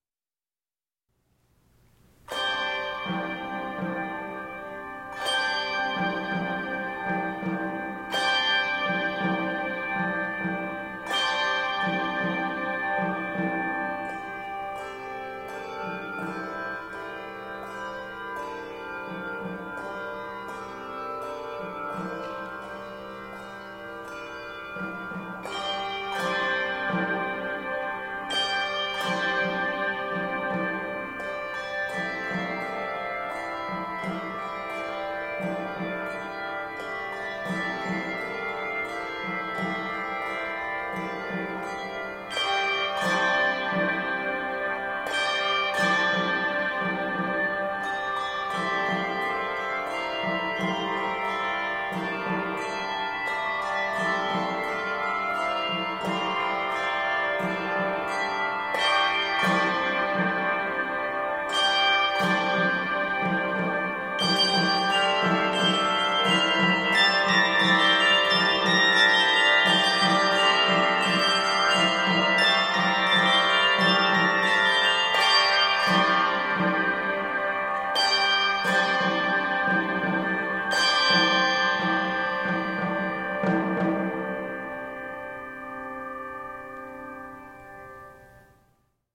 3-5 octaves of handbells
an optional hand drum